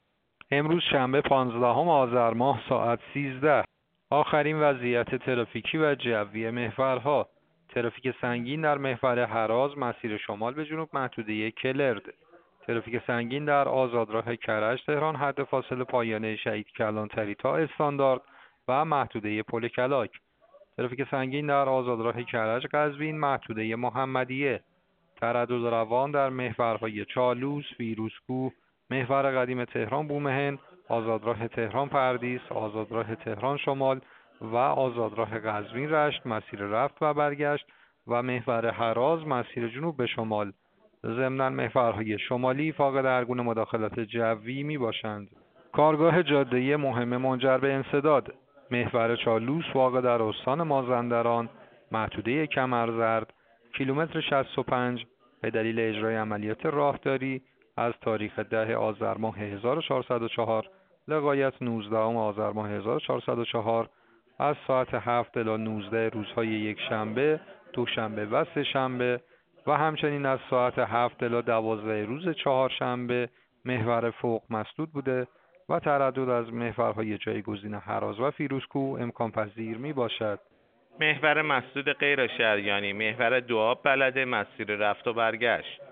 گزارش رادیو اینترنتی از آخرین وضعیت ترافیکی جاده‌ها ساعت ۱۳ پانزدهم آذر؛